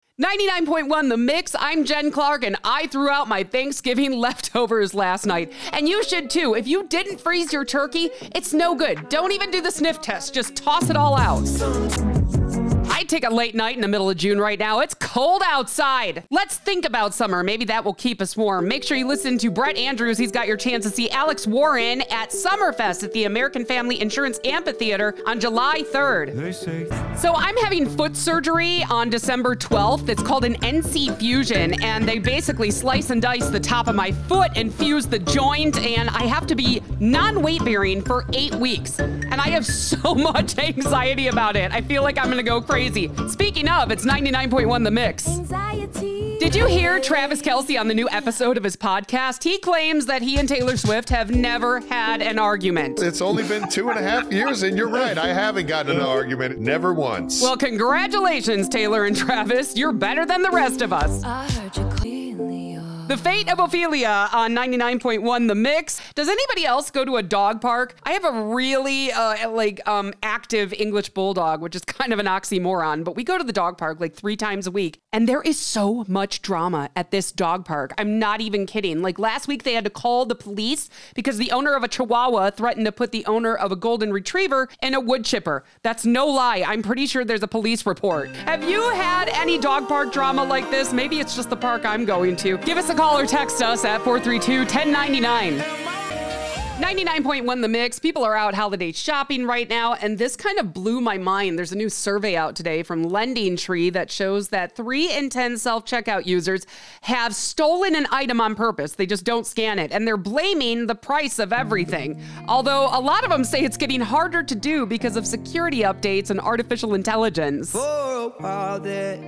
Radio Demo
Middle Aged